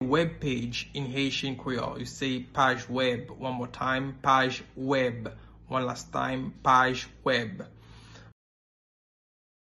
Listen to and watch “Paj wèb” audio pronunciation in Haitian Creole by a native Haitian  in the video below:
33.How-to-say-Web-page-in-Haitian-Creole-–-Paj-web-pronunciation-1-1.mp3